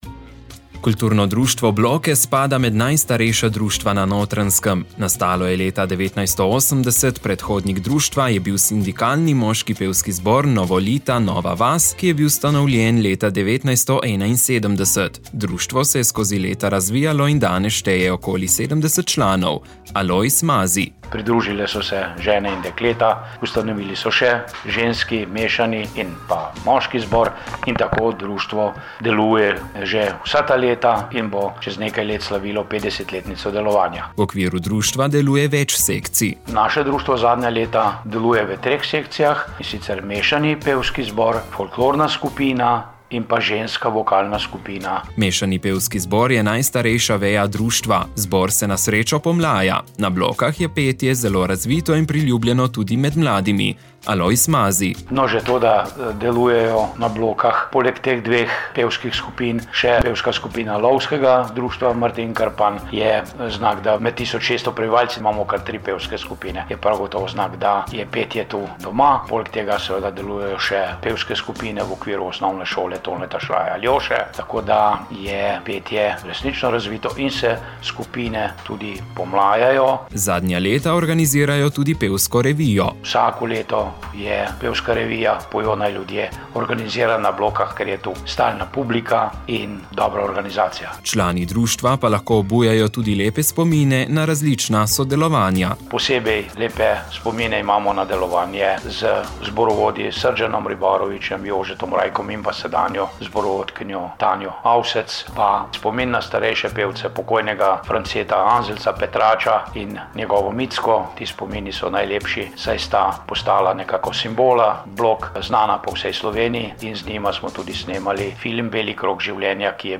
O društvu, njegovi zgodovini, načrtih in pomenu za Bločane smo se pogovarjali